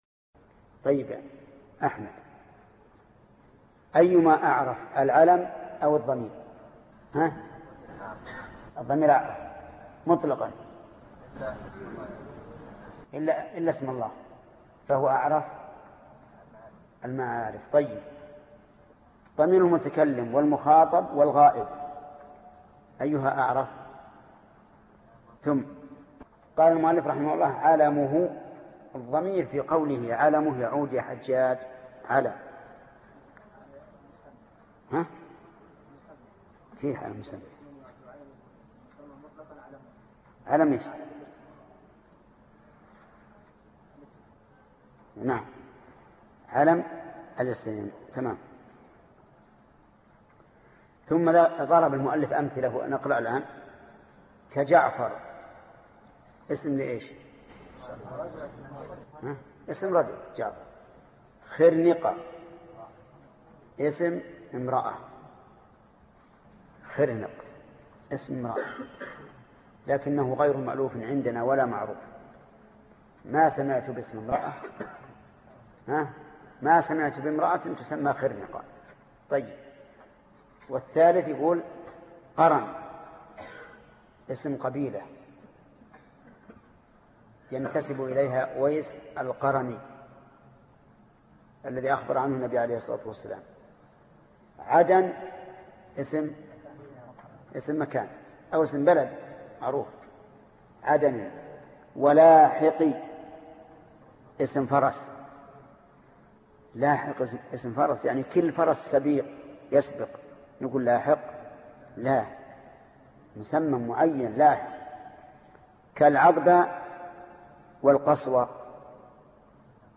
الدرس 57 ( شرح الفية بن مالك ) - فضيلة الشيخ محمد بن صالح العثيمين رحمه الله